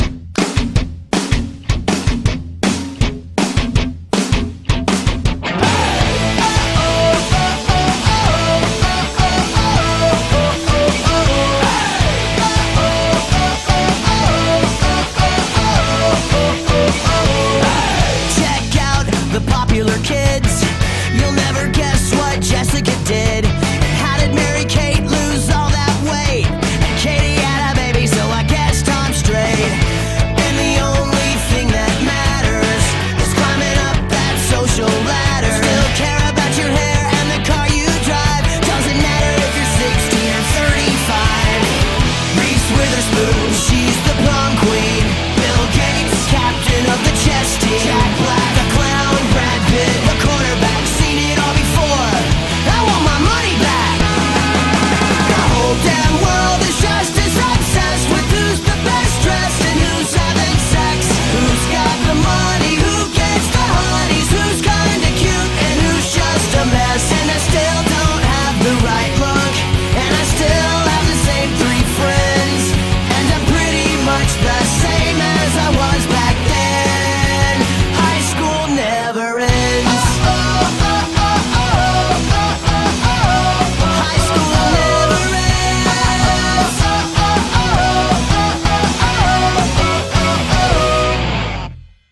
BPM160
Goes from A major to B major.